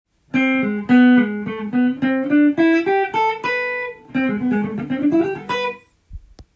フレーズすべてがコードAm7上で使えるものになっています。
このスケールを使えばジャズっぽい雰囲気が出せるので重宝されます。